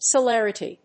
音節ce・ler・i・ty 発音記号・読み方
/səlérəṭi(米国英語), sʌˈlerʌti:(英国英語)/